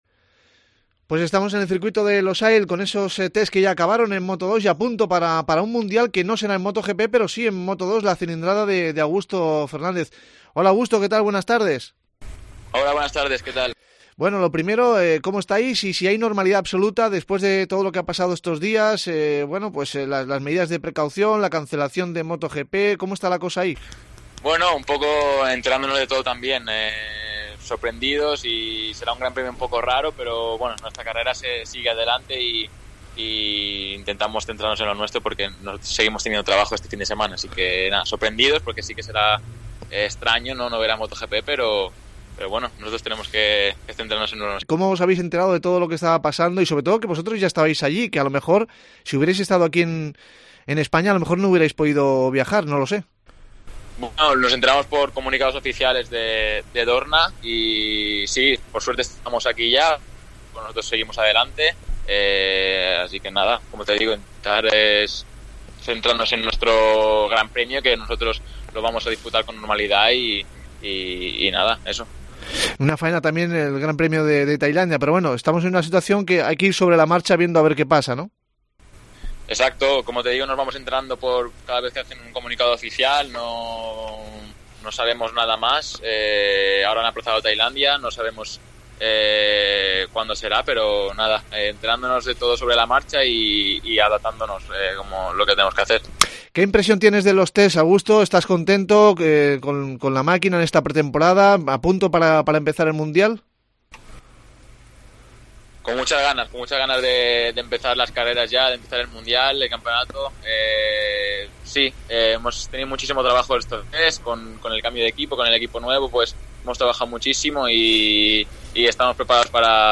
Con él hablamos de todo lo que está pasando por el coronavirus y la anulación del Gran Premio de Thailandia, y de sus expectativas para el Mundial de Moto 2.